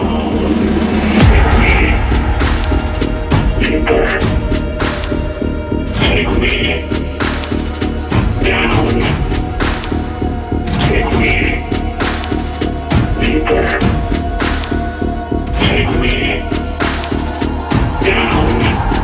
Category: Samsung Ringtones